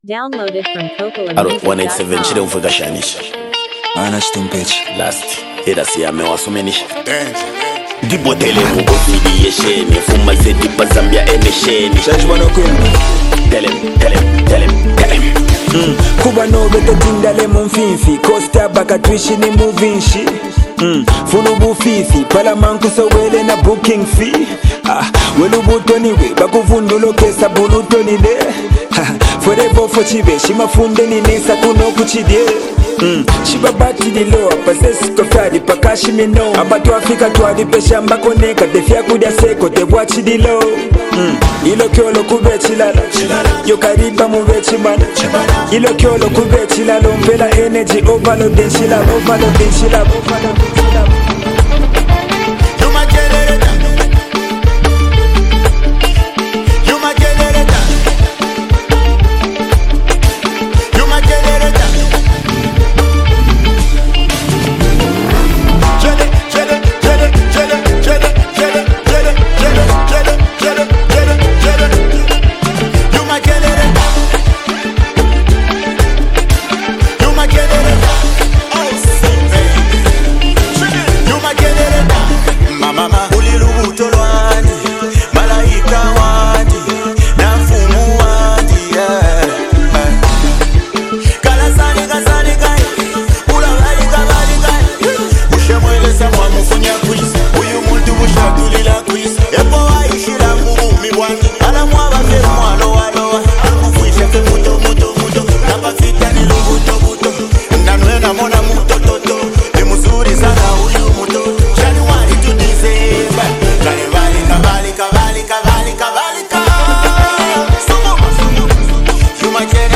is a high-energy track that blends street wisdom